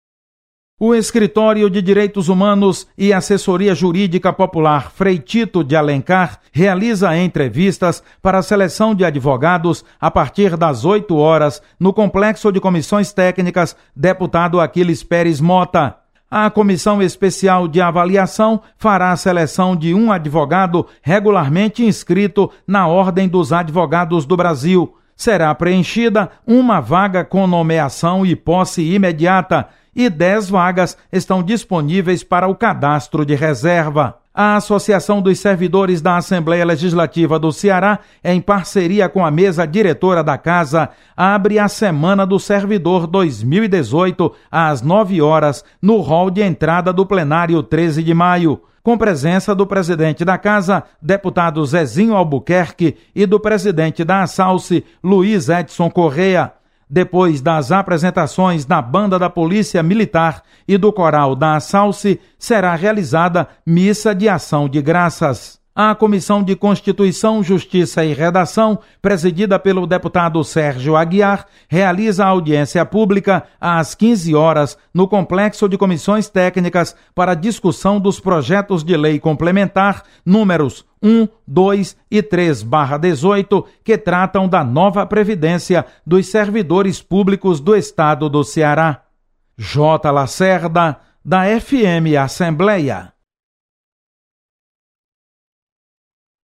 Acompanhe as informações das atividades da Assembleia Legislativa nesta segunda-feira. Repórter